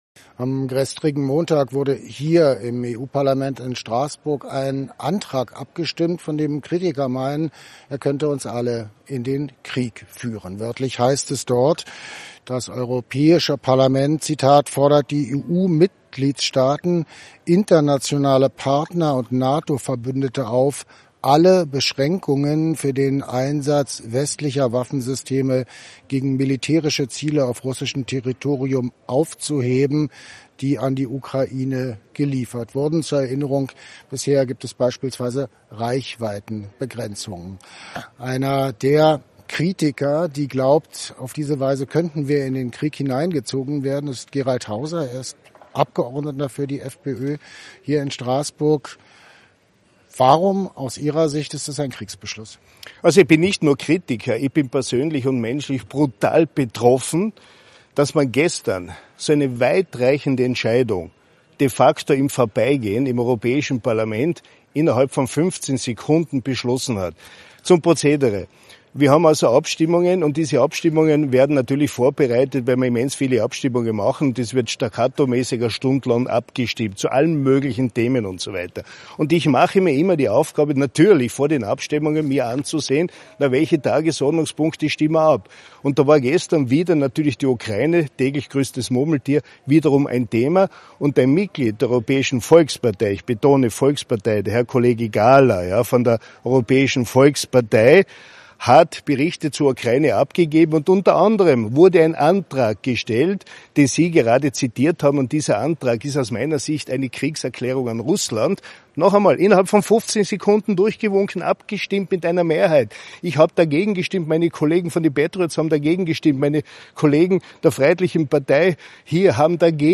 In Straßburg spricht
EU-Abgeordneten Mag. Gerald Hauser.